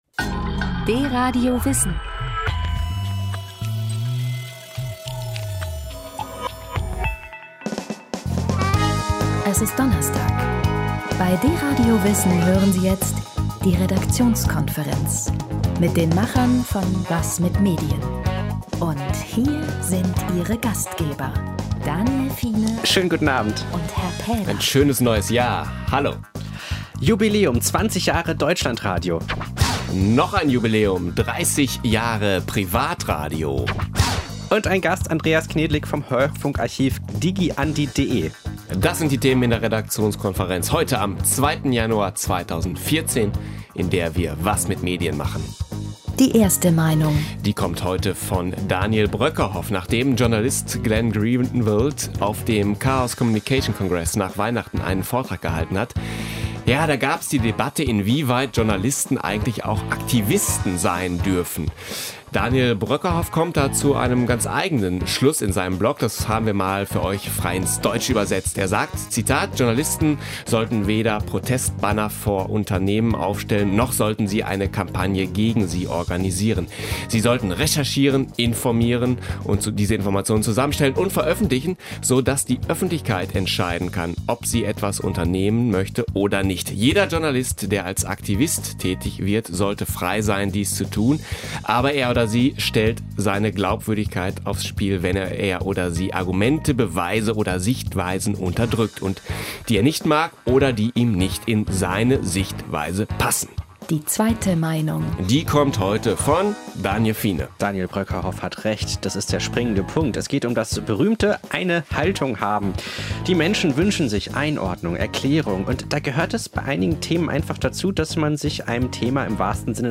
Seid bei der akustischen Deutschlandreise in dieser "Was mit Medien"-Ausgabe dabei. Ihr hört eine Sendung von DRadio Wissen.